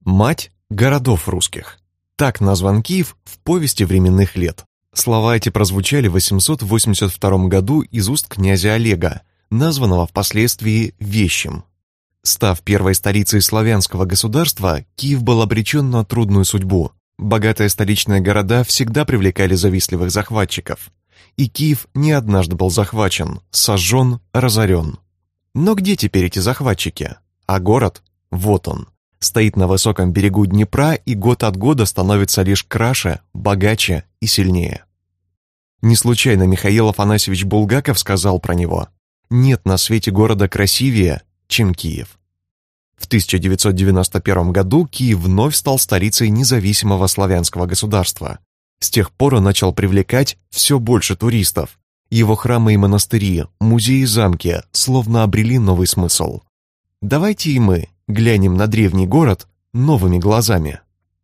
Аудиокнига Киев. 10 мест, которые вы должны посетить | Библиотека аудиокниг